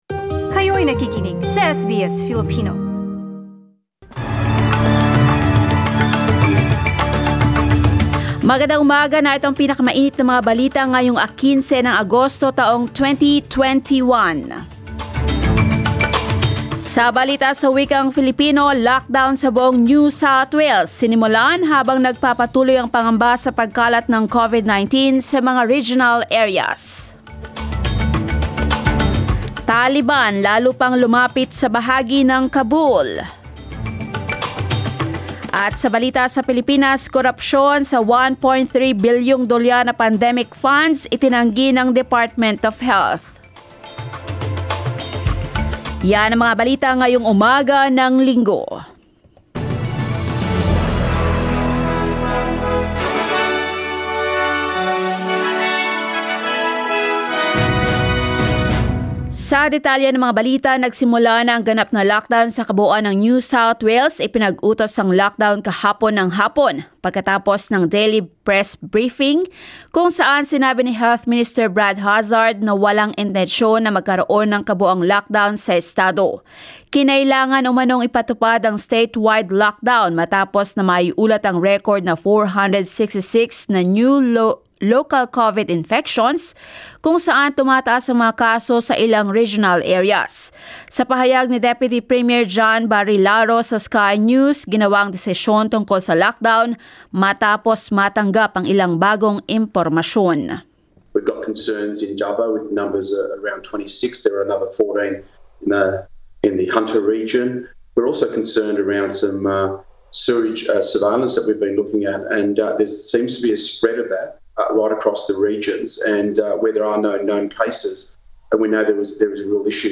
SBS News in Filipino, Sunday 15 August